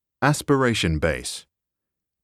[as-puh-rey-shuh n] [beys]